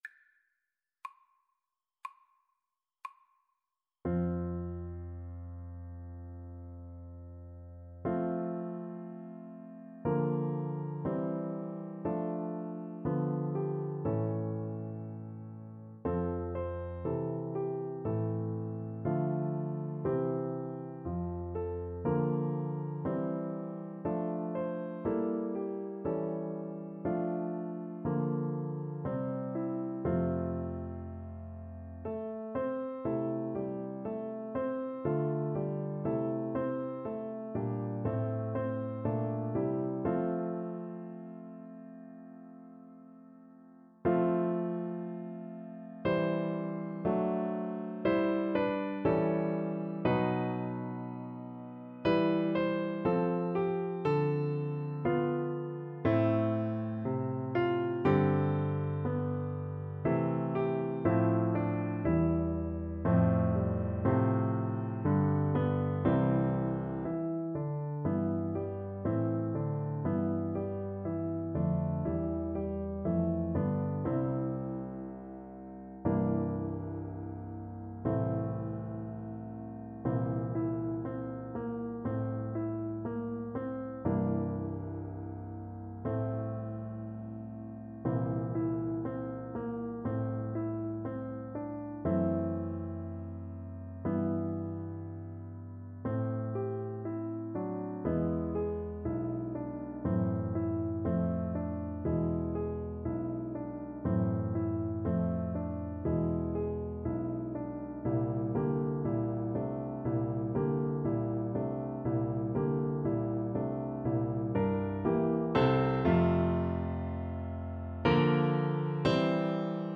Play (or use space bar on your keyboard) Pause Music Playalong - Piano Accompaniment Playalong Band Accompaniment not yet available reset tempo print settings full screen
Adagio
F major (Sounding Pitch) (View more F major Music for Baritone Voice )
Classical (View more Classical Baritone Voice Music)